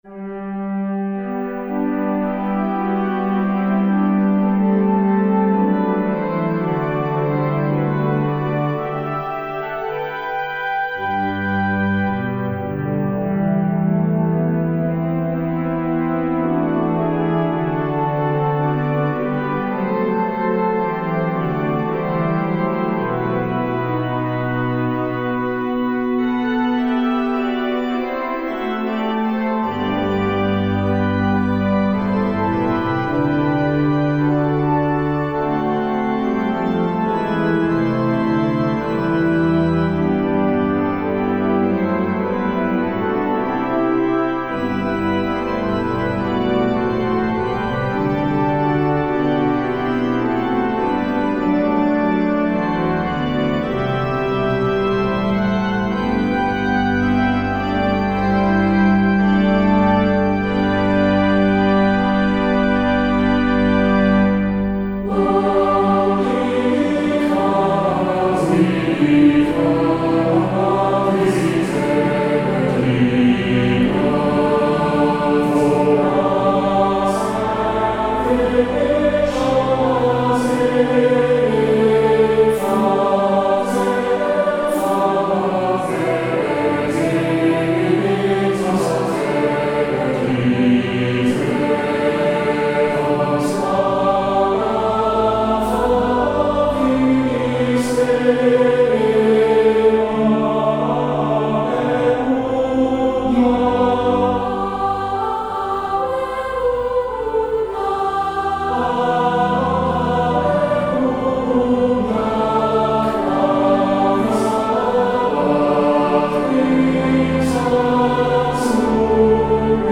Free descant to the hymn tune HELMSLEY - 'Lo, he comes with clouds descending'